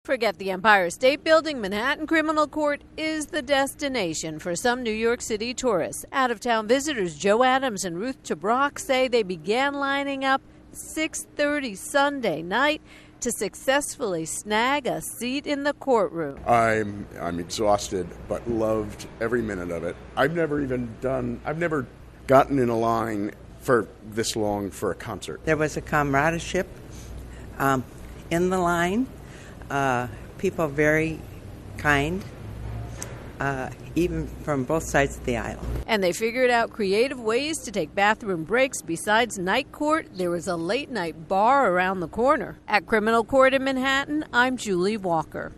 reports on the Trump trial tourists in the courtroom.